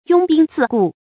拥兵自固 yōng bīng zì gù
拥兵自固发音